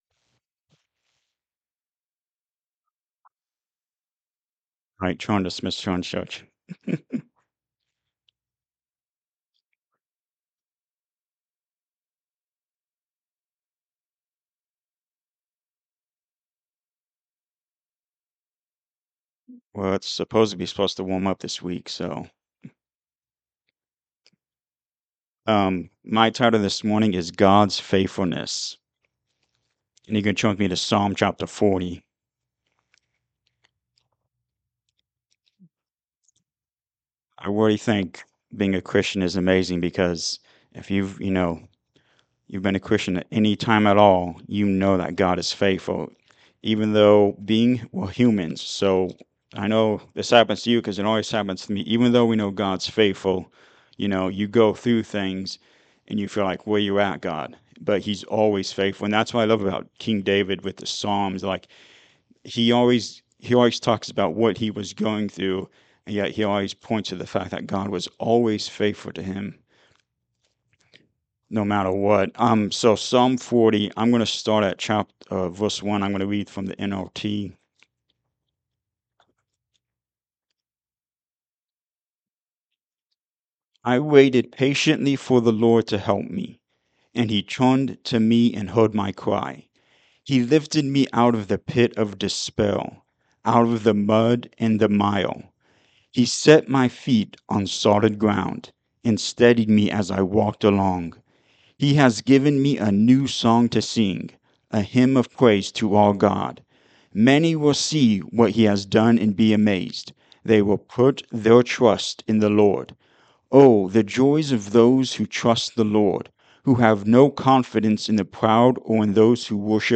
Psalm 40 and 86 Service Type: Sunday Morning Service He is true to His Word.
Sunday-Sermon-for-February-1-2026.mp3